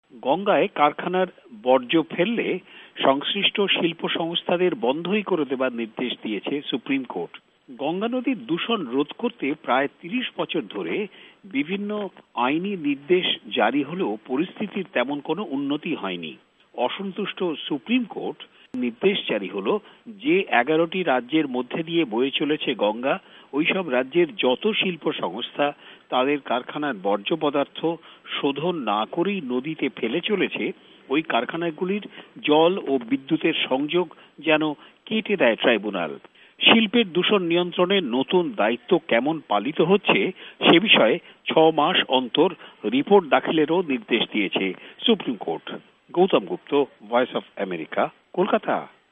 ভয়েস অব আমেরিকার কলকাতা সংবাদদাতাদের রিপোর্ট